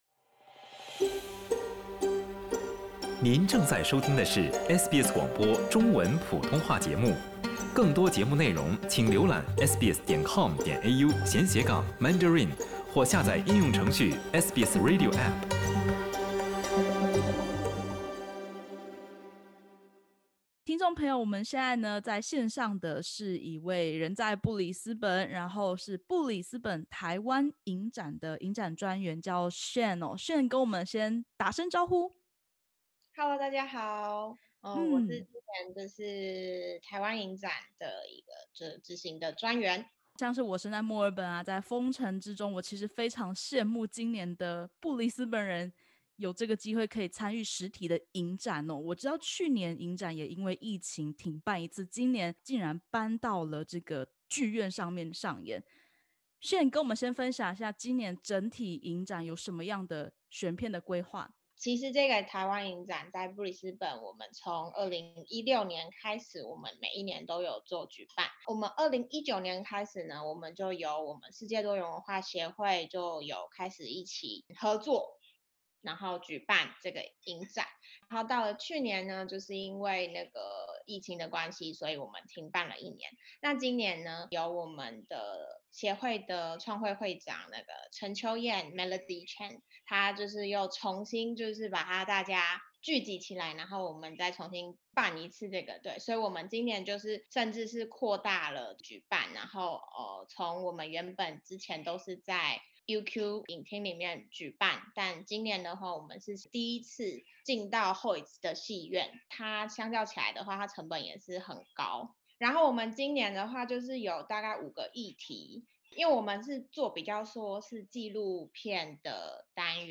继去年受COVID-19疫情停办一次后，2021年布里斯班台湾影展扩大举行，首次登上商业戏院、甚至要到州议会播映。点击首图收听采访音频。